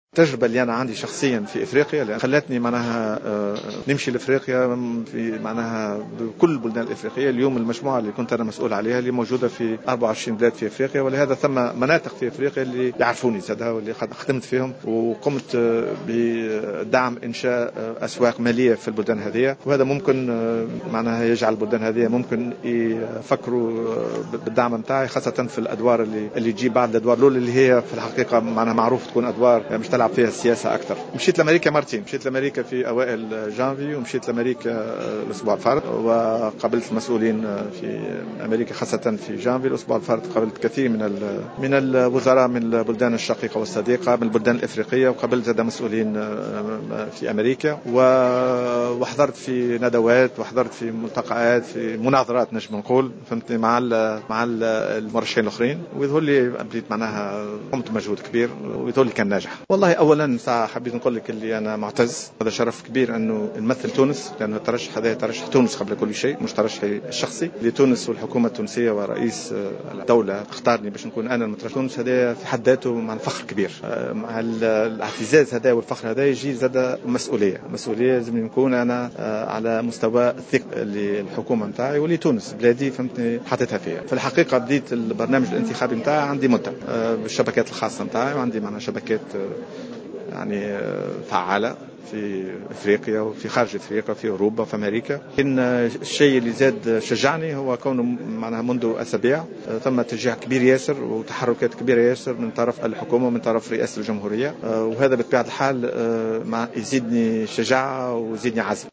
أعلن وزير المالية السابق جلول عياد خلال ندوة صحفية عقدها اليوم الخميس 30 أفريل 2015 بالعاصمة عن دعم عدة دول افريقية و أوروبية لترشحه لرئاسة البنك الإفريقي للتنمية.